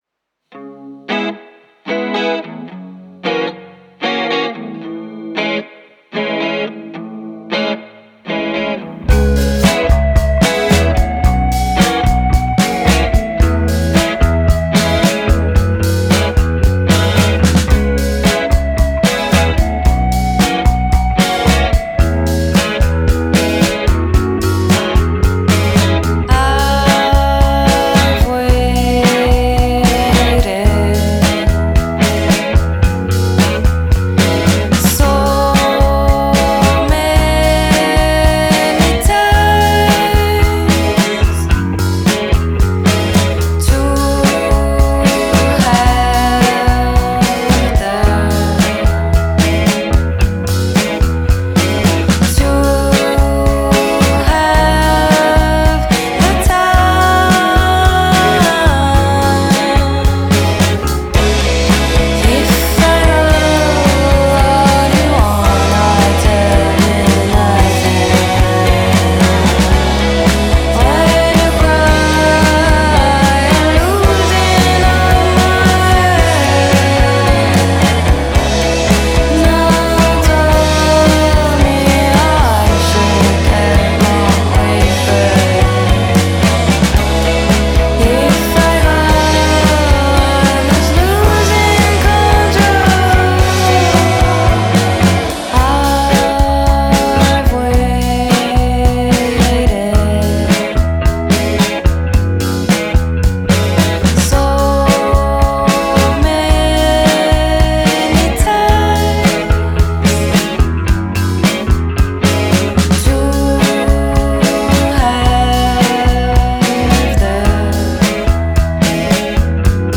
Characterized as Dreampop